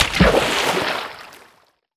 water_splash_object_body_05.wav